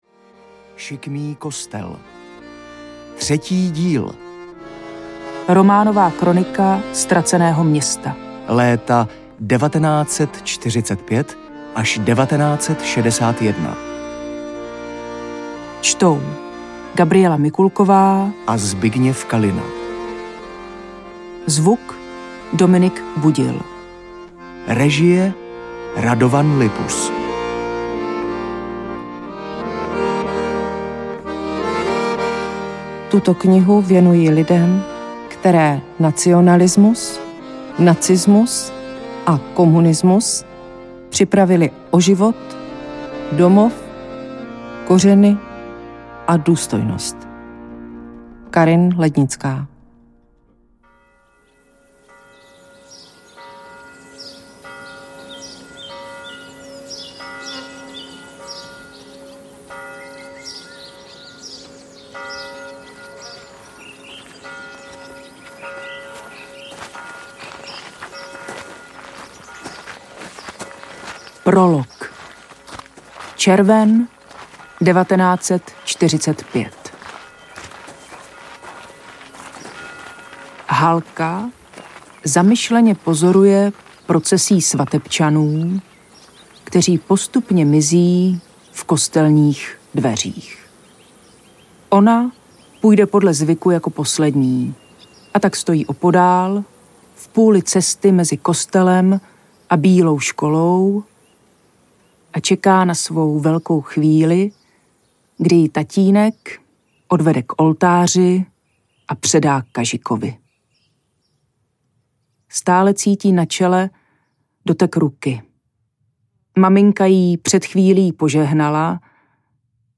Šikmý kostel 3 audiokniha
Ukázka z knihy
sikmy-kostel-3-audiokniha